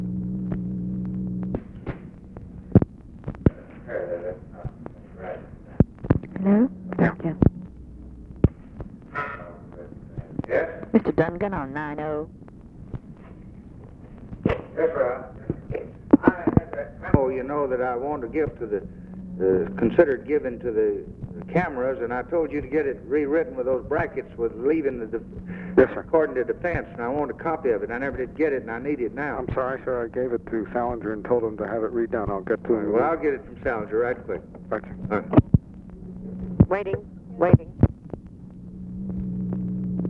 Conversation with RALPH DUNGAN, January 23, 1964
Secret White House Tapes